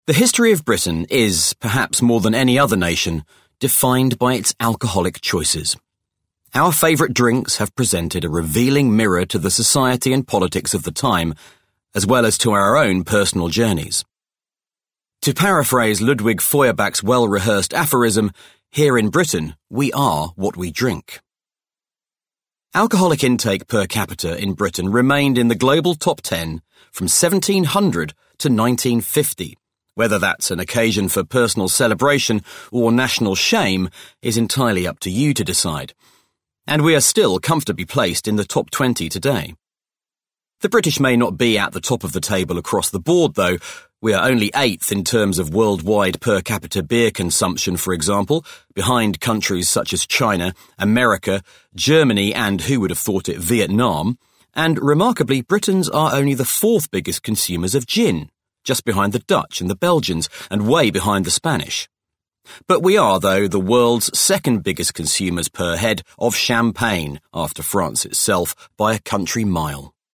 40's Neutral/RP,
Friendly/Confident/Natural